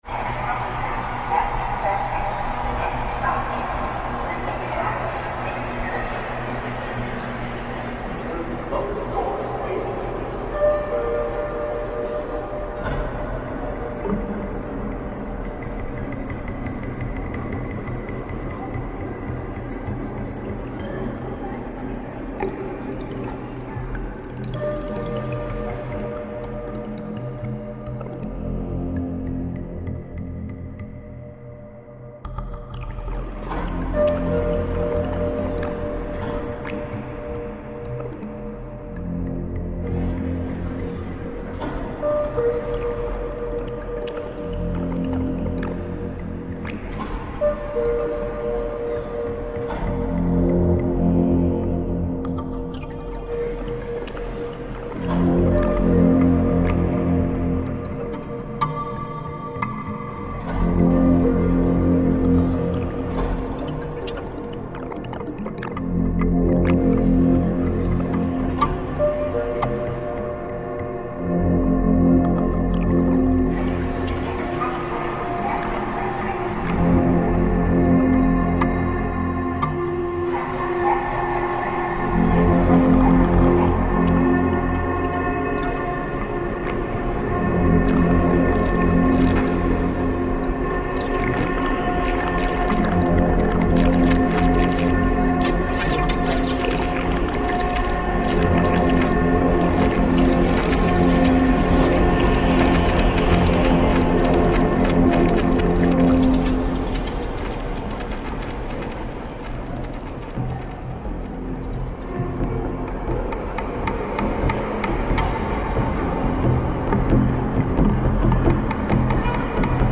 New York metro from Roosevelt Island reimagined